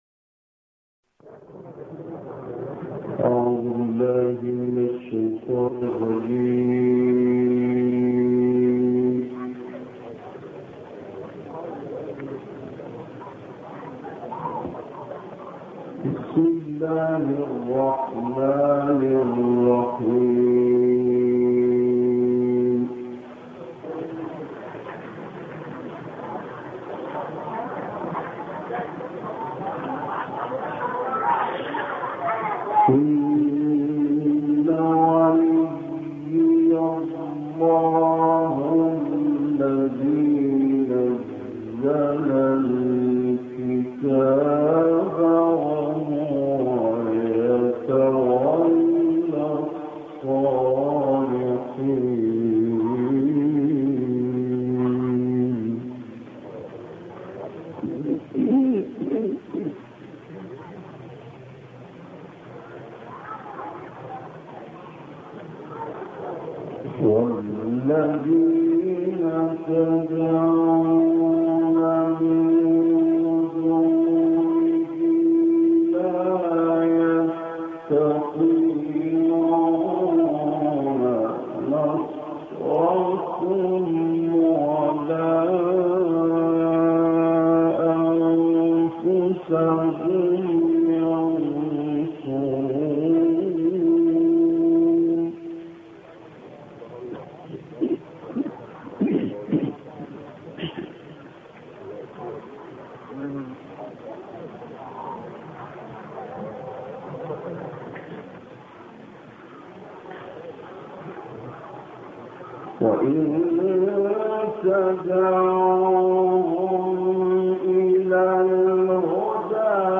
سوره اعراف والانفال را با صدای او می شنویم: